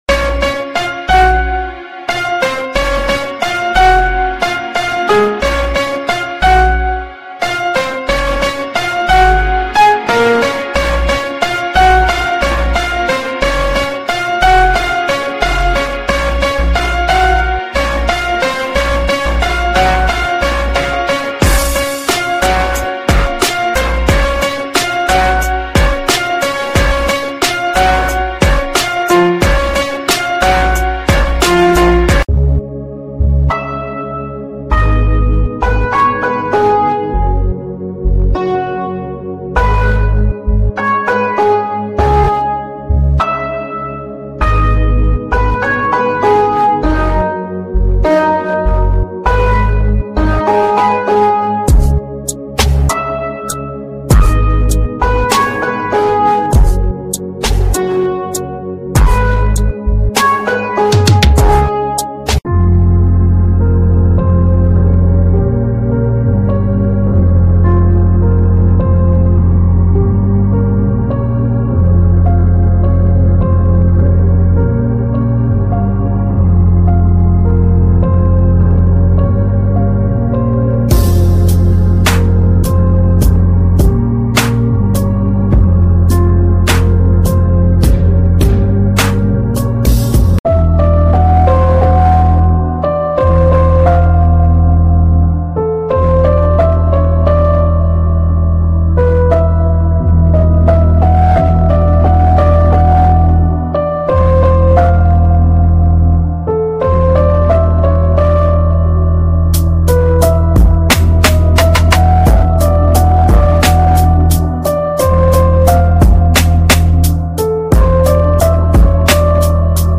🇨🇦 Montreal Walking Tour ｜ sound effects free download